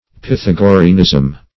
Search Result for " pythagoreanism" : The Collaborative International Dictionary of English v.0.48: Pythagoreanism \Pyth`a*go"re*an*ism\, n. The doctrines of Pythagoras or the Pythagoreans.
pythagoreanism.mp3